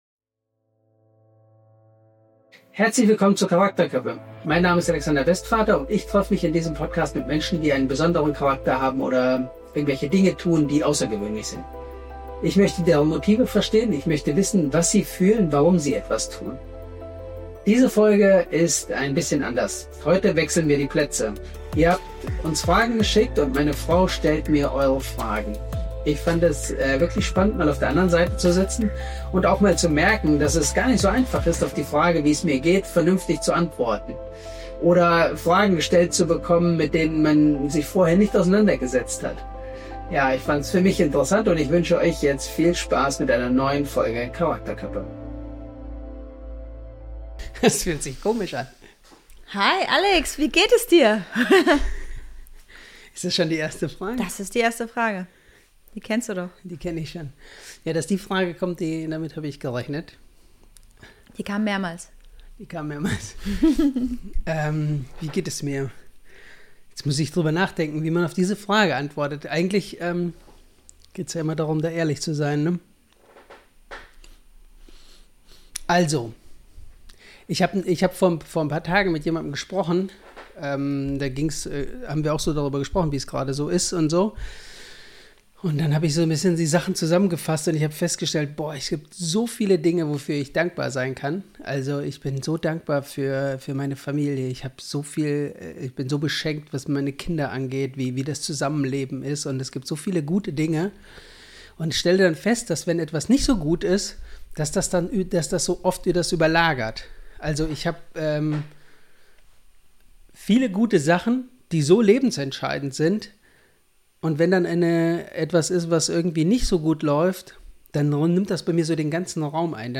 Ein ehrliches, persönliches Gespräch